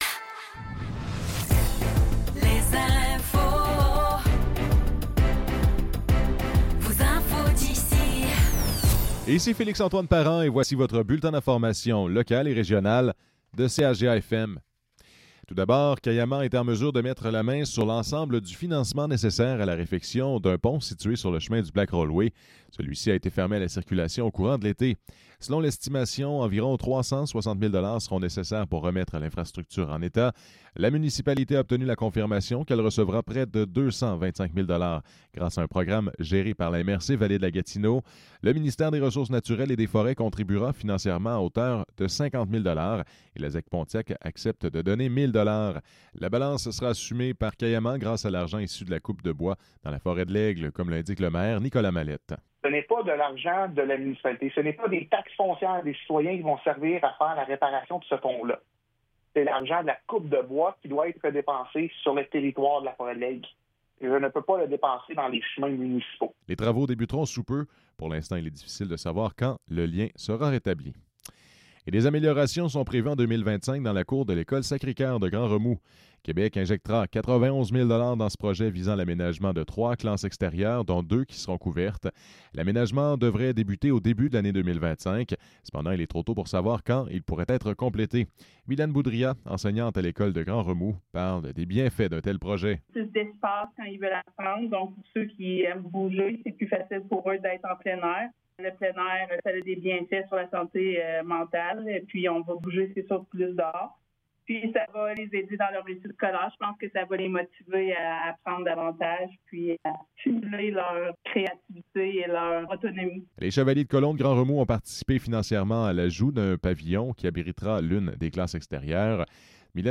Nouvelles locales - 26 décembre 2024 - 15 h